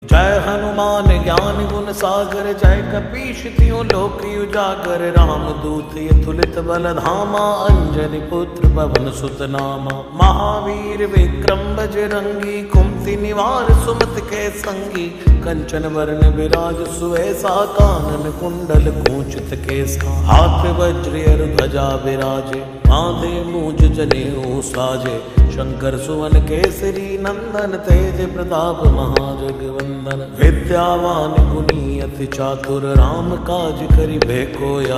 Slowed Reverb
• Category: Devotional / Hanuman Bhajan